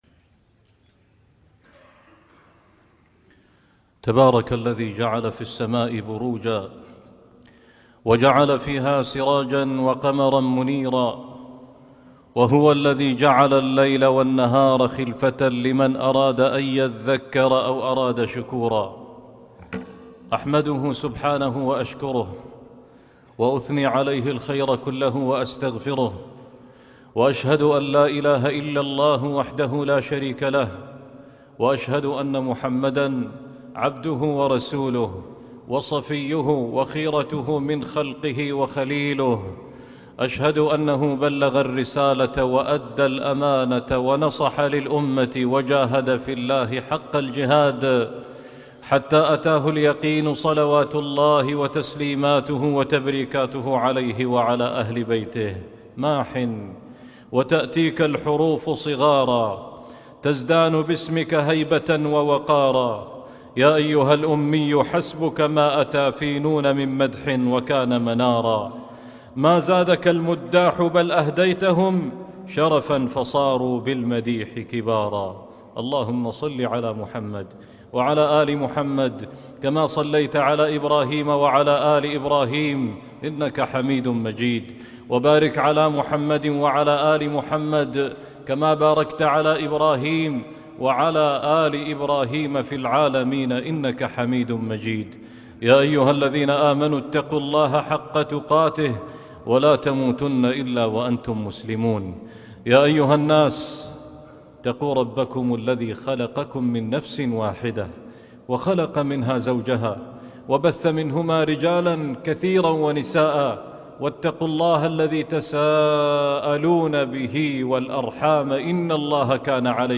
قرآن الجود - خطبة وصلاة الجمعة